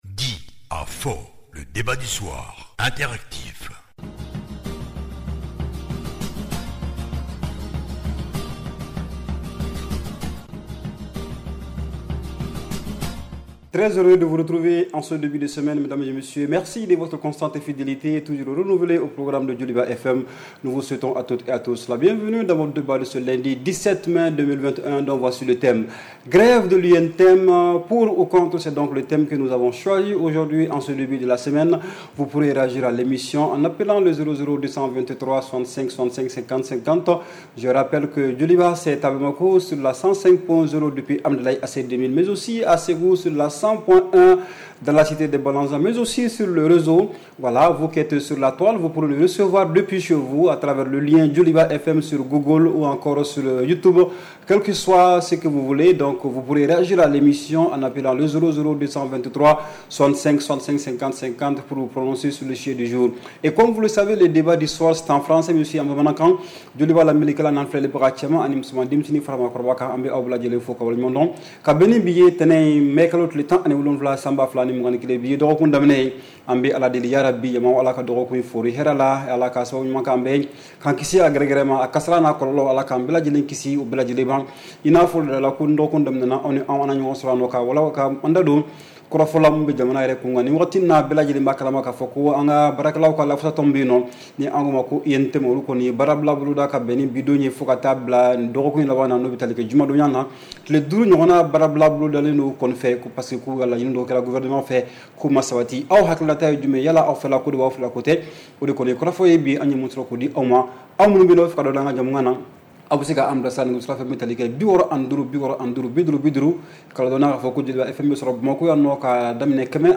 REPLAY 17/05 – « DIS ! » Le Débat Interactif du Soir